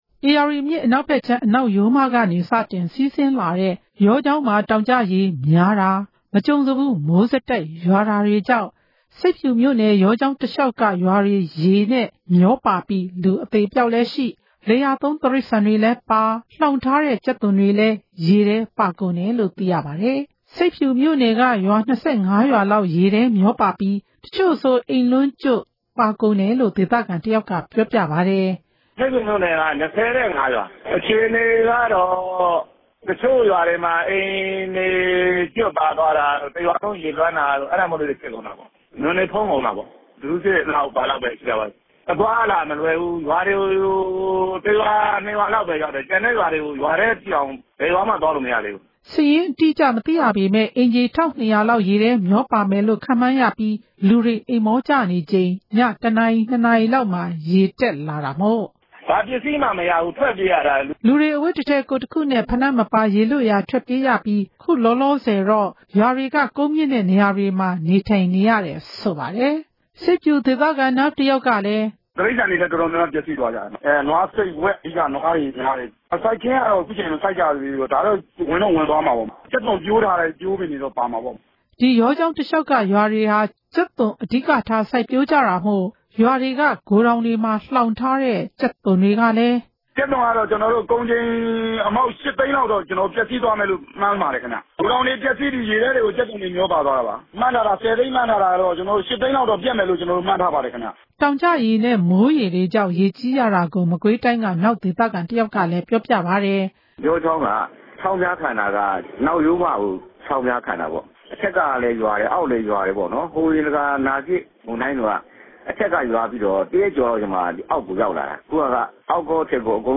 စုစည်းတင်ပြချက်။